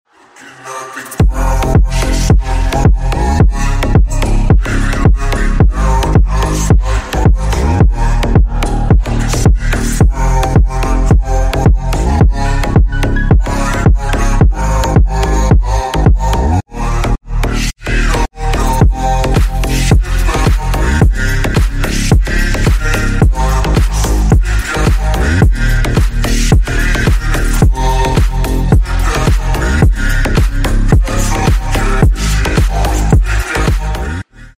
Клубные Рингтоны » # Громкие Рингтоны С Басами
Рингтоны Ремиксы » # Танцевальные Рингтоны